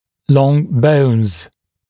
[lɔŋ bəunz][лон боунз]длинные кости